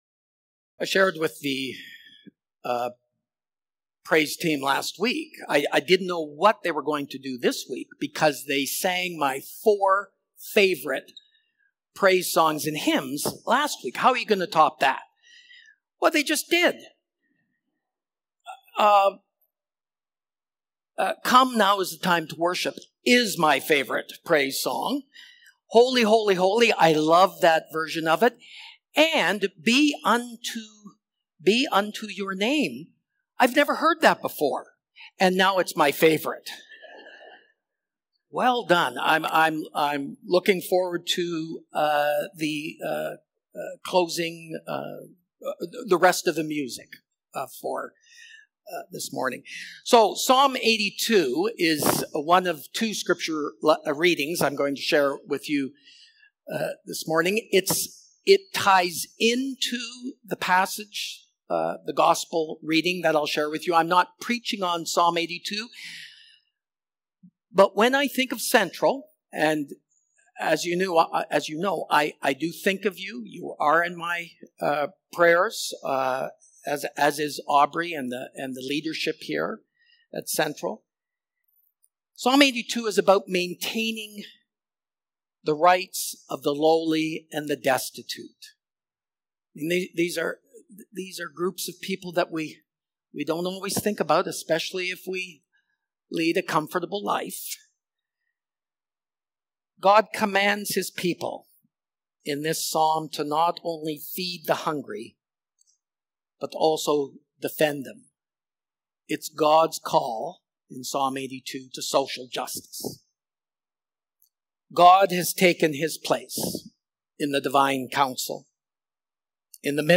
July-13-Sermon.mp3